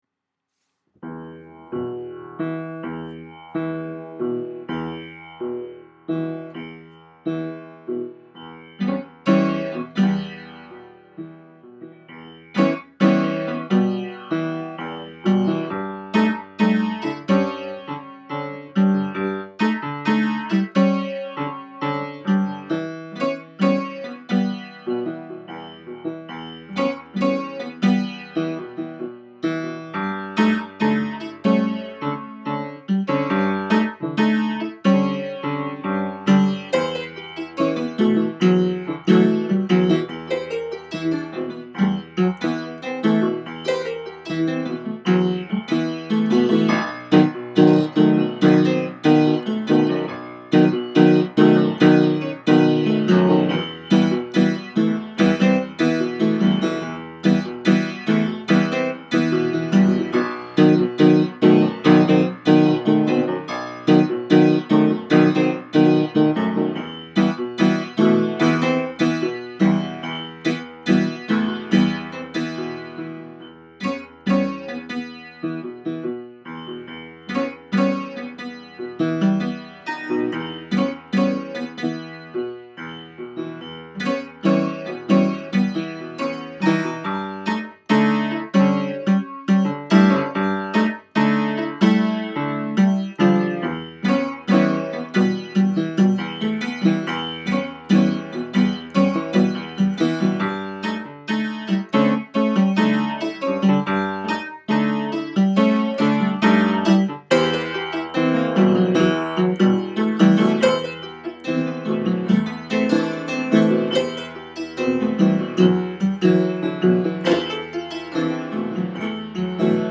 piano improv: moroccan nights
It's repetitive and clumsy -- but it was only about 15 minutes from inspiration to recording -- so you can't ask for much.
I really like being able to just put the laptop on the piano bench beside me to capture impromptu stuff like this.